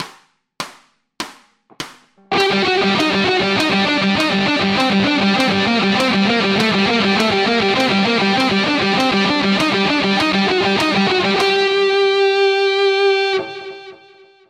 Затем открытая струна, второй палец, открытая струна, первый.
Аудио (100 УВМ)